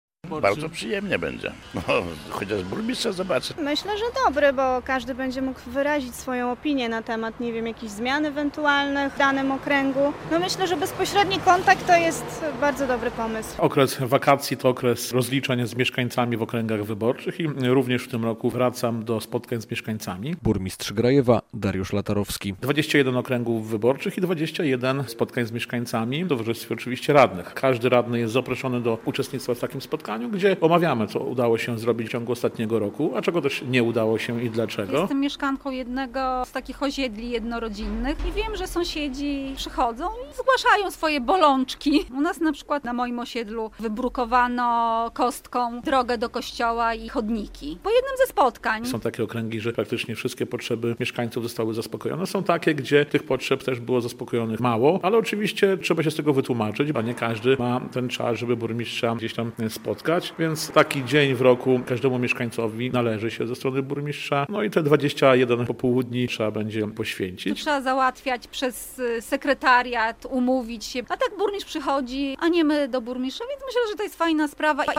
Burmistrz Grajewa chce mieć lepszy kontakt z mieszkańcami miasta - zaplanował spotkania z nimi - relacja